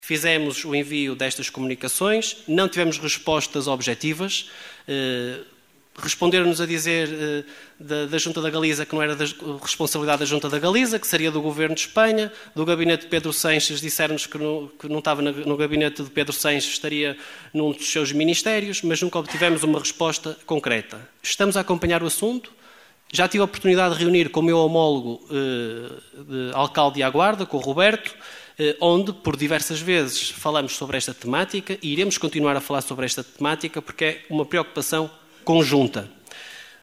O parque eólico que está projetado para o sul da Galiza, a dívida à Luságua, a questão do CET, o Ferry Boat e o estado “vergonhoso”  em que se encontram as ruas e estradas municipais foram algumas das questões deixadas pelo deputado da coligação O Concelho em Primeiro (OCP) Jorge Nande ao Presidente da Câmara Rui Lages no período antes da ordem do dia da última assembleia municipal de Caminha.